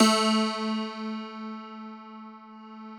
53q-pno09-A1.aif